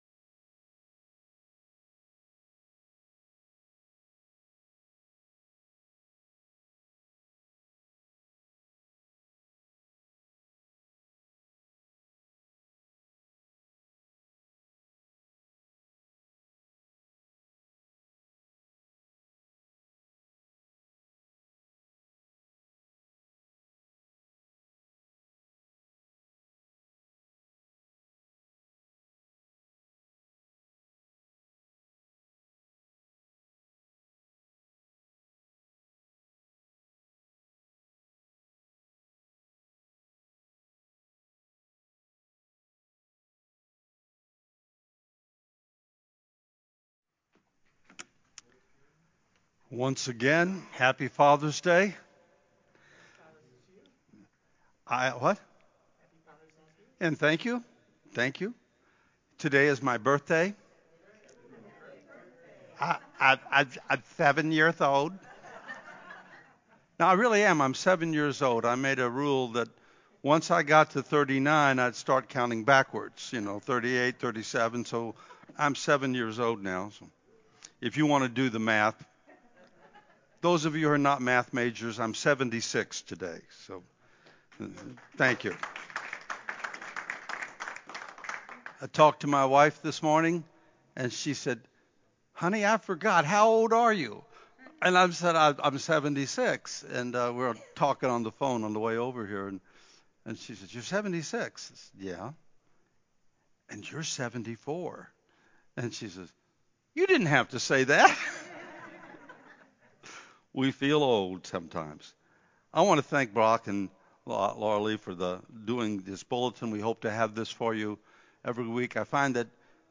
“The List of the Mighty” Sermon
The-List-of-the-Mighty-Sermon-Audio-CD.mp3